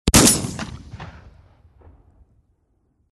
Звуки дробовика
8. Тренировка стрельбы по мишени